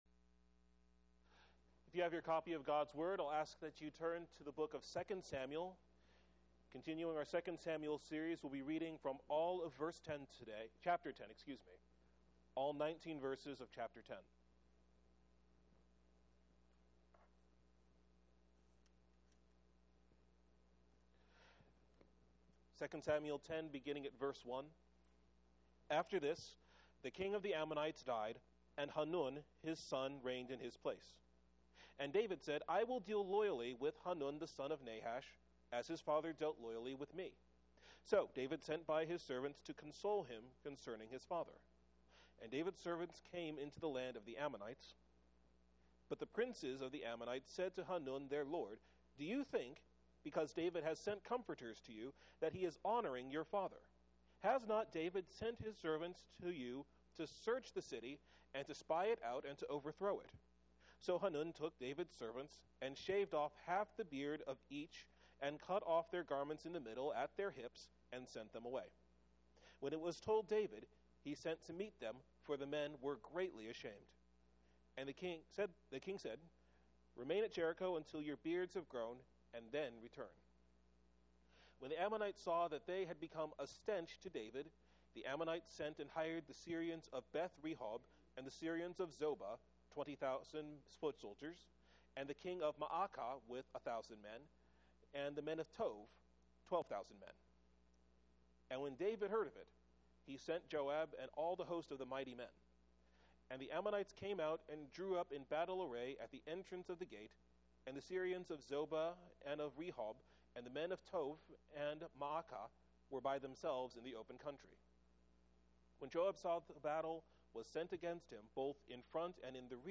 An Entirely Unnecessary War - Providence Reformed Church of Bakersfield